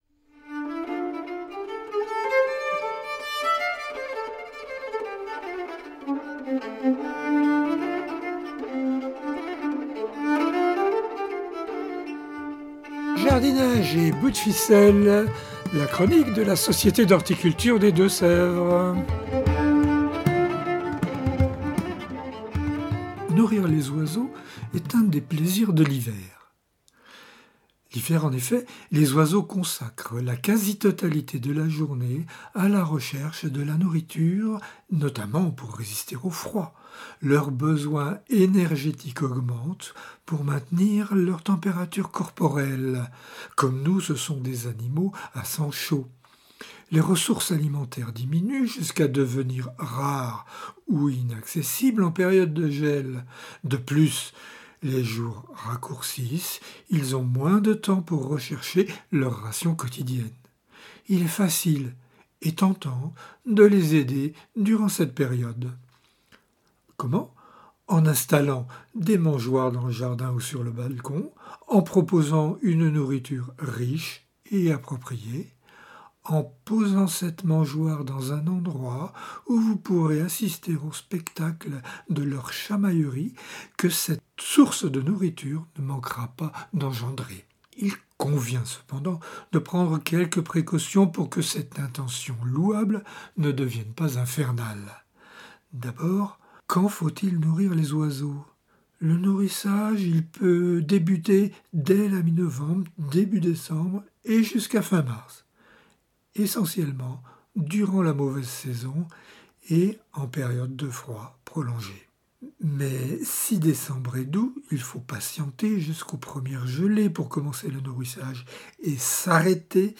Chronique audio - Nourrir les oiseaux - Société d'Horticulture du 79
(Ces chroniques sont diffusées chaque semaine sur les radios D4B et Pigouille Radio)